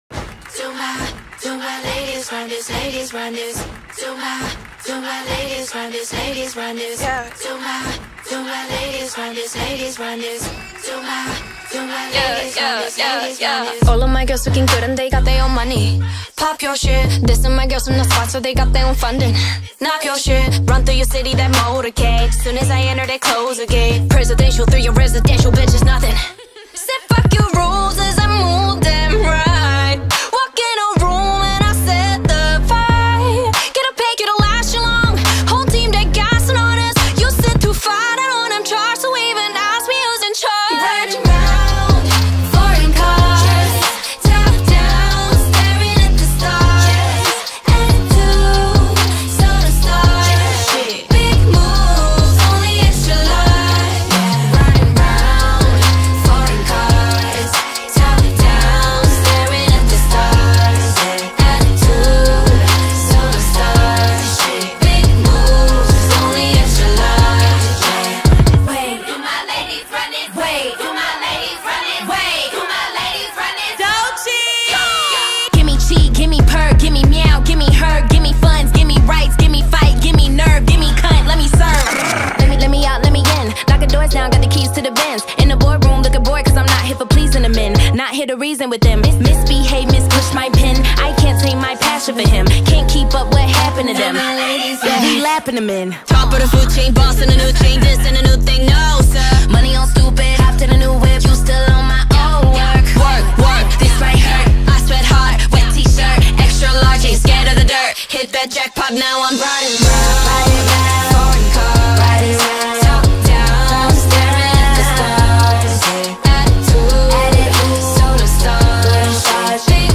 ترکیب پاپ و رپ پایه و اساس این آهنگه